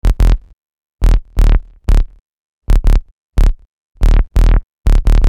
Bass 15.wav